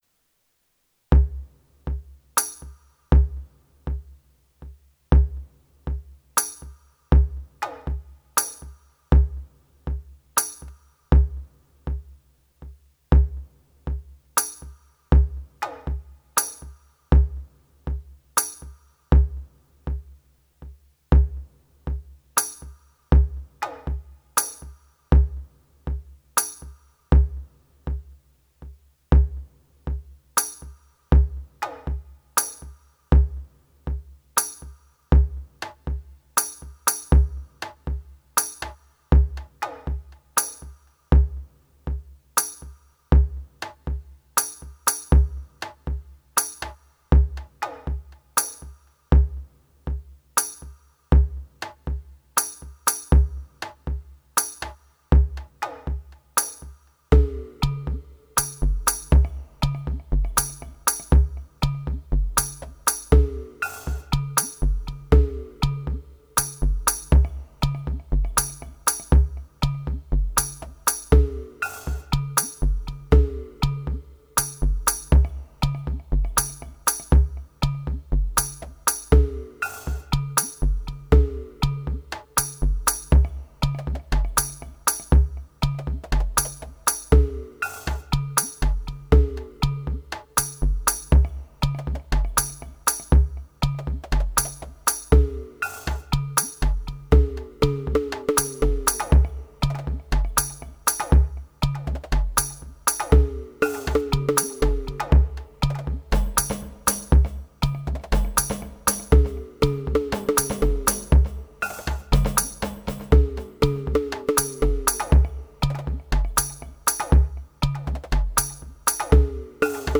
It feelt empty until the pad came in around the middle, and with the bell they added a very mystical layer to the very empy beat. The low howllike pad in the background just fill in a lot of the empty space and it sounds really good.